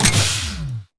laser-turret-deactivate-04.ogg